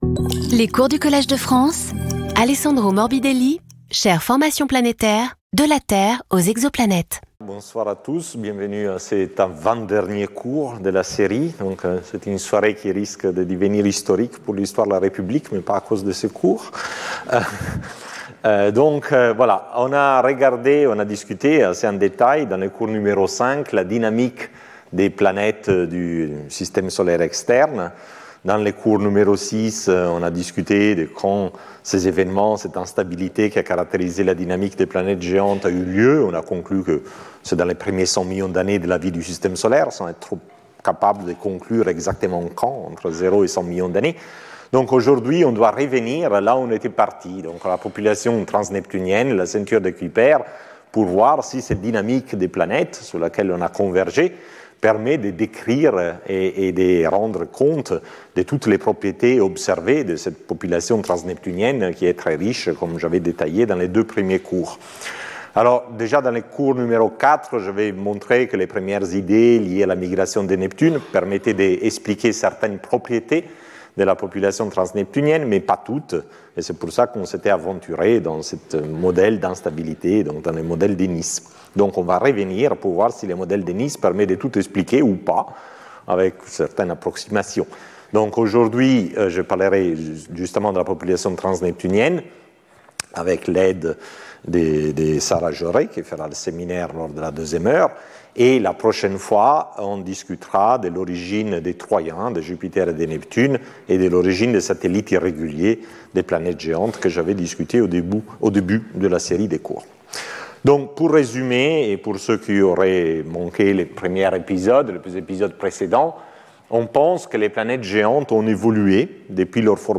Sauter le player vidéo Youtube Écouter l'audio Télécharger l'audio Lecture audio Cours d’une heure, suivi du séminaire.